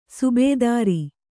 ♪ subēdāri